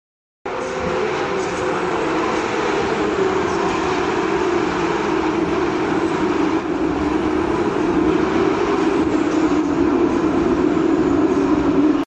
Carrera Cup 992 911 Gt3 Sound Effects Free Download